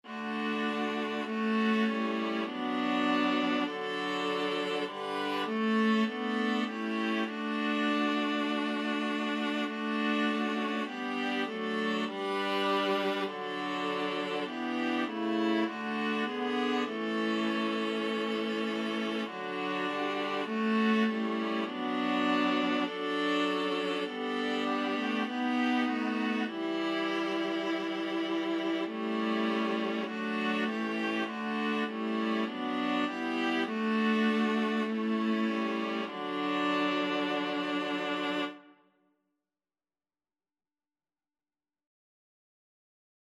Free Sheet music for Viola Quartet
D major (Sounding Pitch) (View more D major Music for Viola Quartet )
4/4 (View more 4/4 Music)
Viola Quartet  (View more Easy Viola Quartet Music)
Classical (View more Classical Viola Quartet Music)
abide_with_me_4VLA.mp3